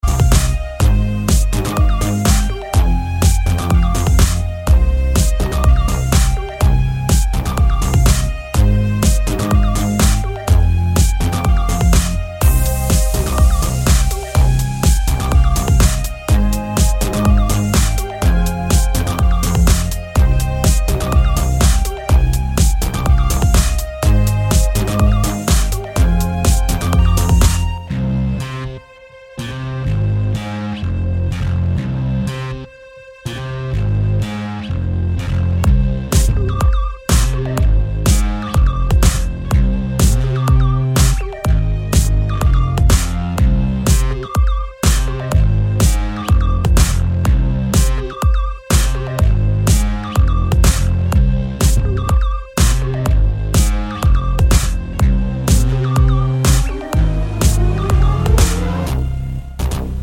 no Backing Vocals Finnish 3:46 Buy £1.50